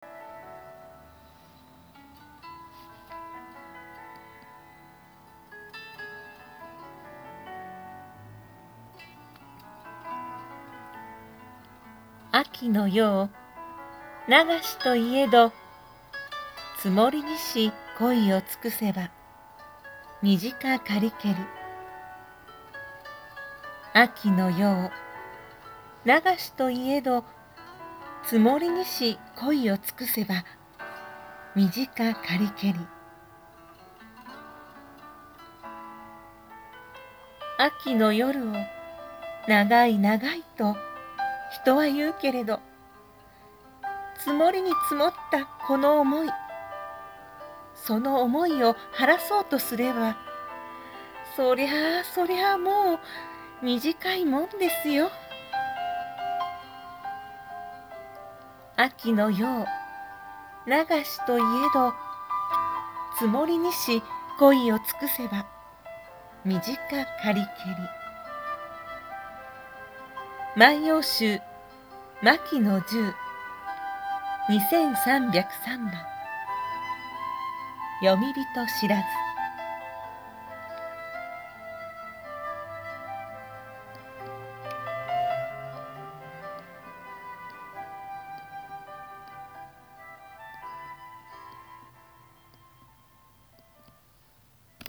母の元から旅立つ万葉歌 阿倍老人（あべのおきな）語り